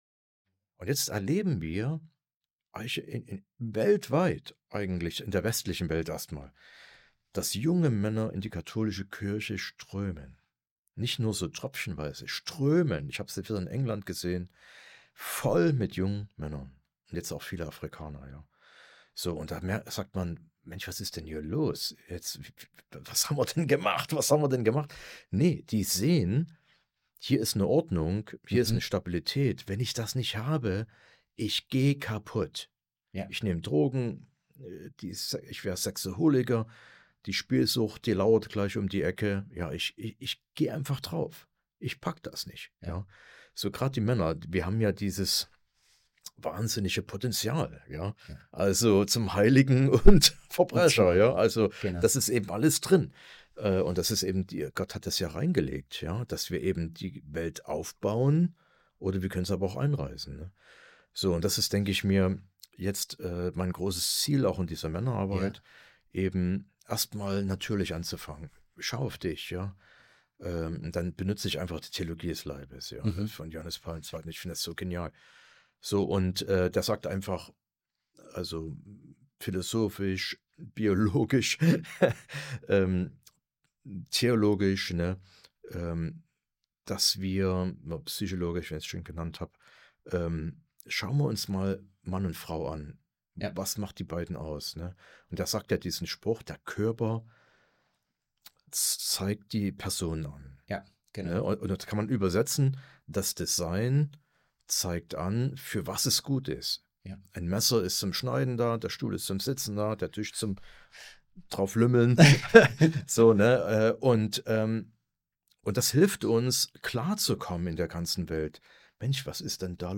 Interview 03) ~ KathoCast - Was glauben Katholiken? Podcast